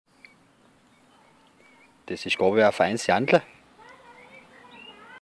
Des-isch-gorawe-a-feins-Jandle.mp3